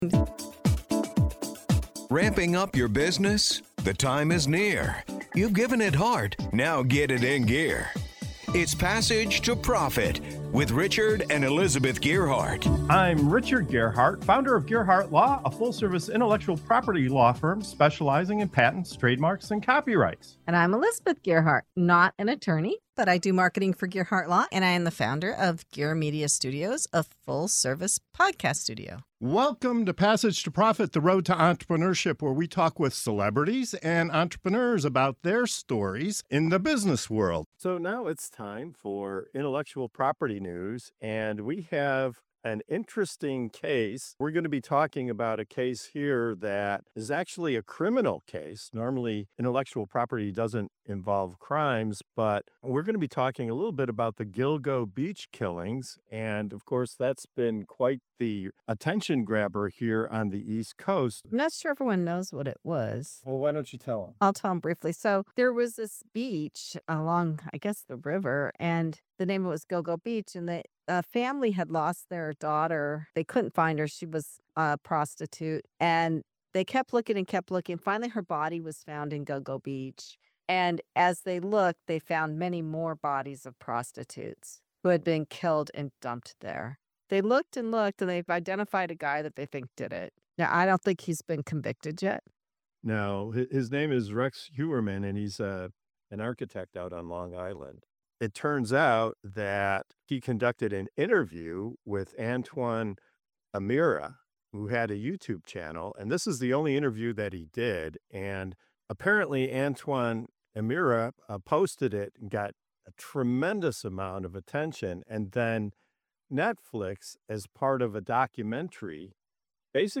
Was Netflix entitled to use the footage under fair use, or did they cross the legal line by skipping permission and payment? Our panel unpacks the legal, ethical, and entrepreneurial angles of this case—raising big questions about copyright, creators’ rights, and whether lawsuits can be an opportunity instead of a setback.